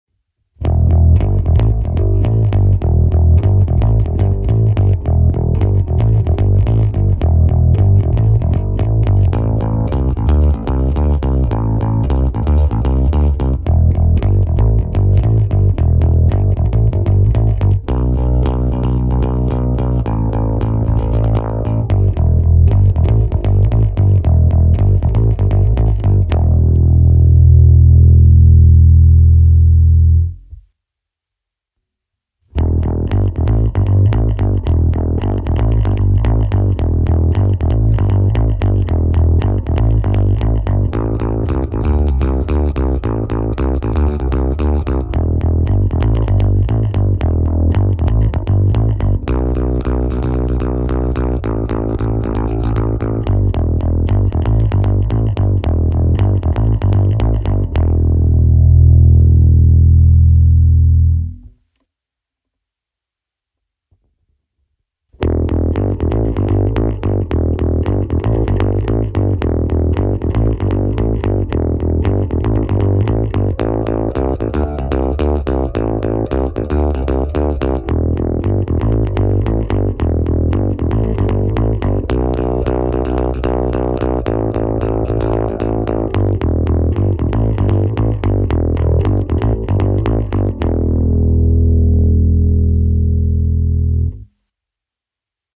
V náhrávkách je mix čistého zvuku se simulací aparátu v softwaru Overloud TH3. Pořadí ukázek: krkový snímač - oba snímače - kobylkový snímač. Plně otevřené tónové clony, vypnuté zvýrazňovače středů, u trsátkové ukázky zapnutý zvýrazňovač atacku. Sorry, trsátkem na basu vůbec nehraju, takže je to takové, jaké to je.
Trsátkem